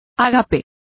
Complete with pronunciation of the translation of banquet.